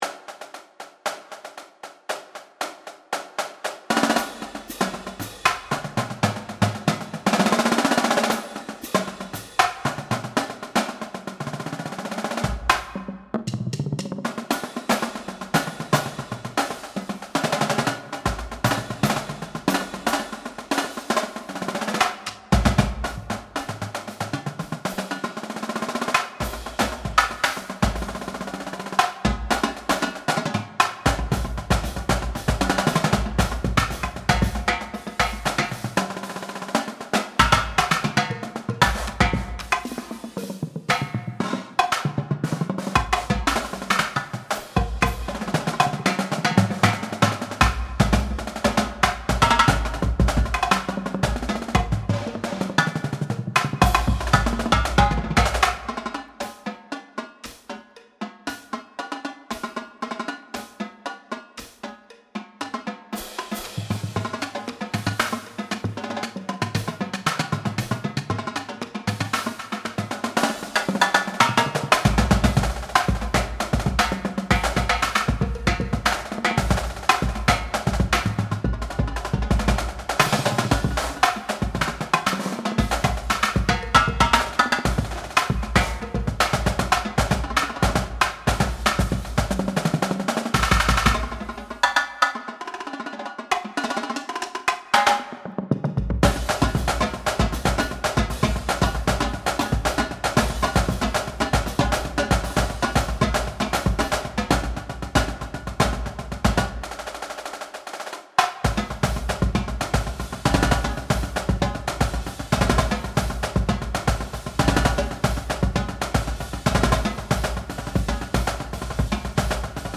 UMass Cadence 2022 - UMass Drumline